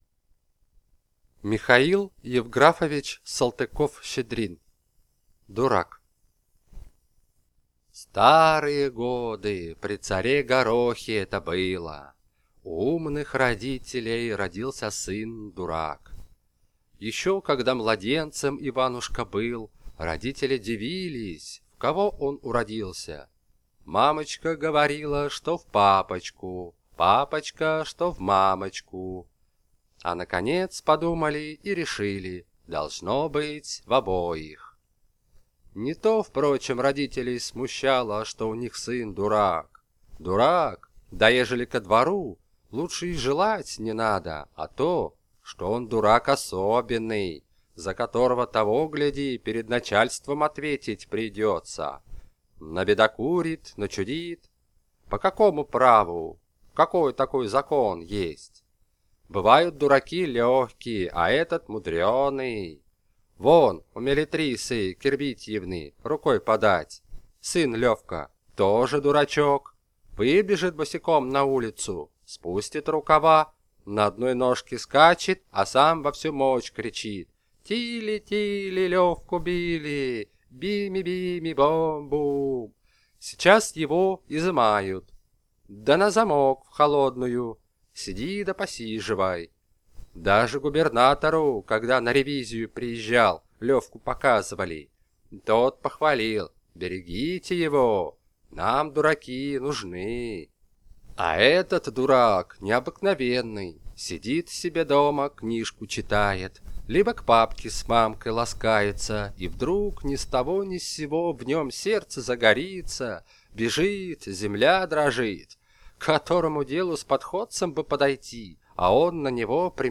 Аудиокнига Дурак | Библиотека аудиокниг